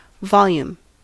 Ääntäminen
US : IPA : /ˈvɑl.jum/ UK : IPA : /ˈvɒl.juːm/